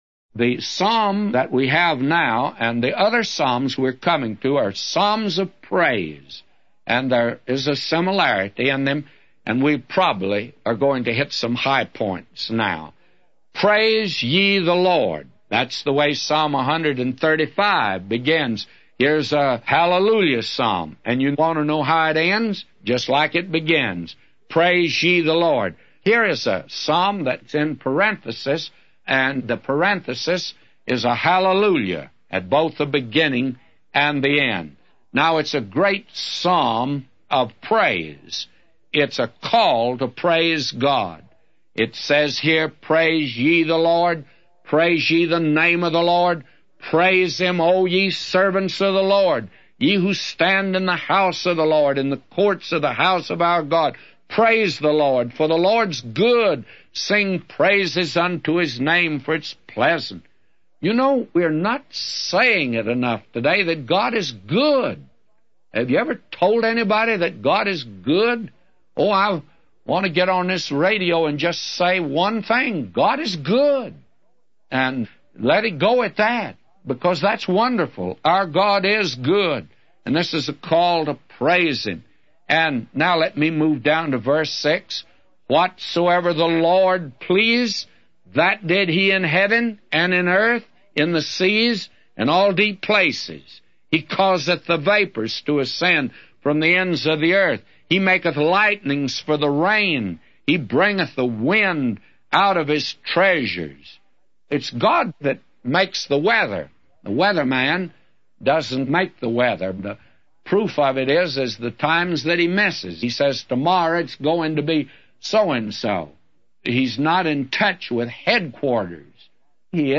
A Commentary By J Vernon MCgee For Psalms 135:1-999